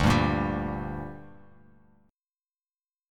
Dsus4#5 chord